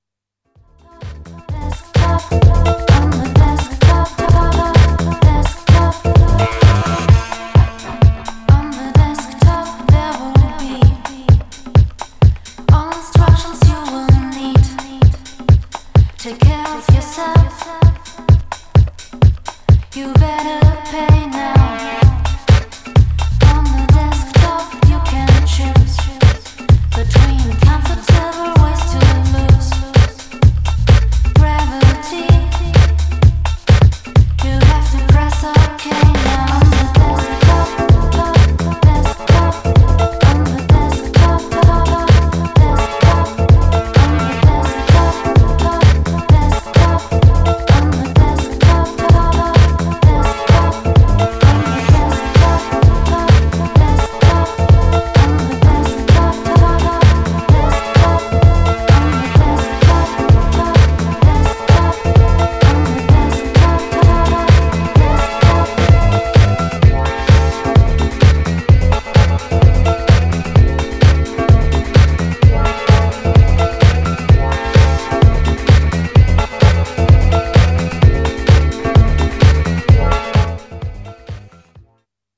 Styl: House